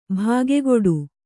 ♪ bhāgegoḍu